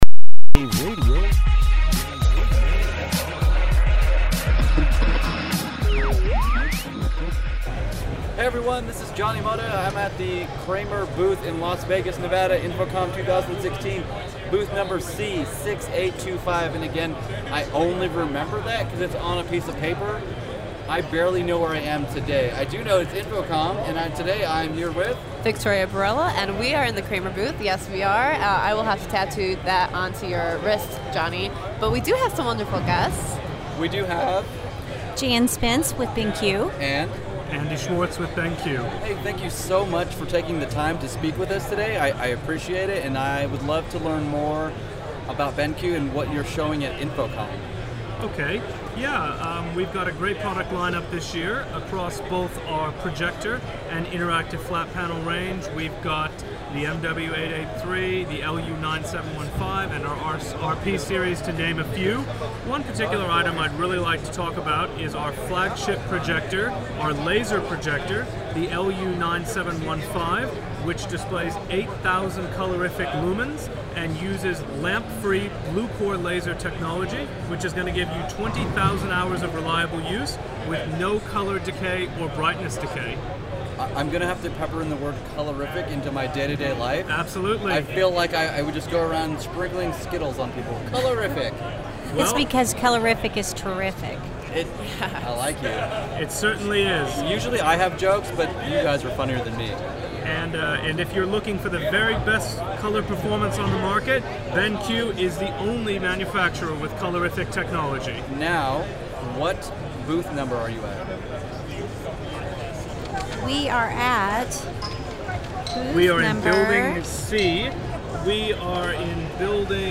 Interview
at Official Day 1 at InfoComm 2016